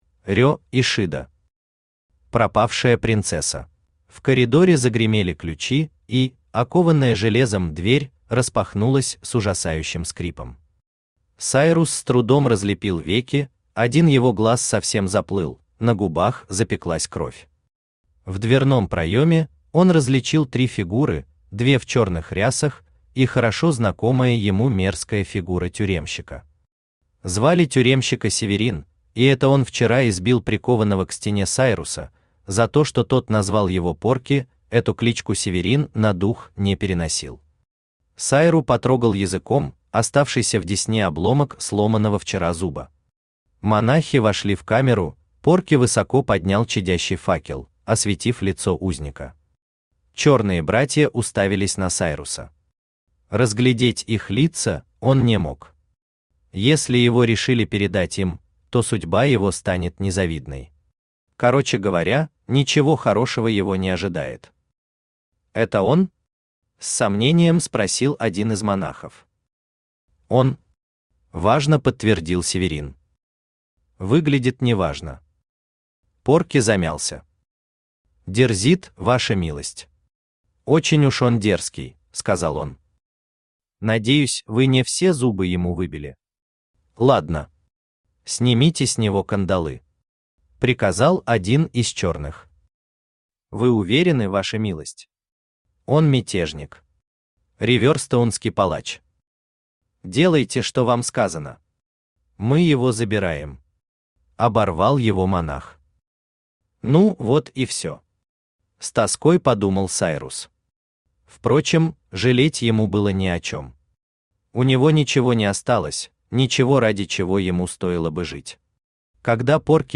Aудиокнига Пропавшая принцесса Автор Рё Ишида Читает аудиокнигу Авточтец ЛитРес.